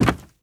STEPS Wood, Creaky, Walk 29.wav